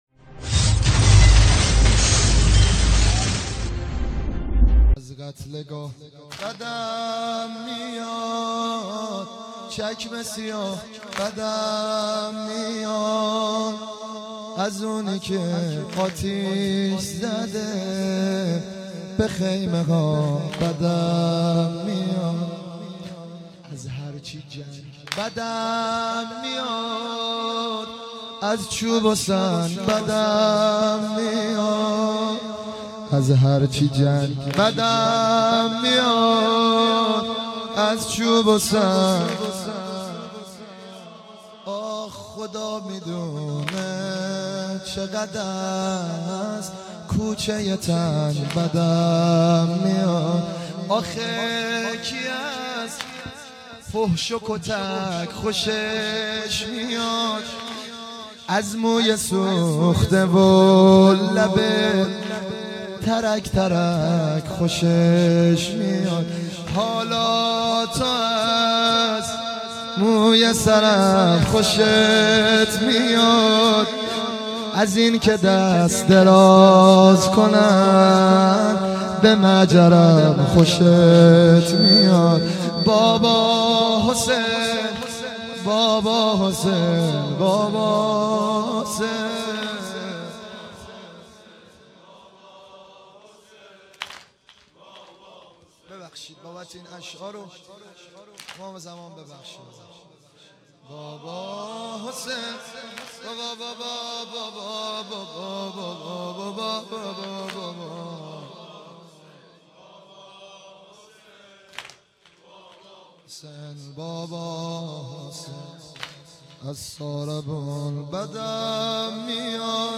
سنگین | از قتلگاه بدم میاد، چکمه سیاه بدم میاد
مداحی
در سالروز شهادت حضرت رقیه (س)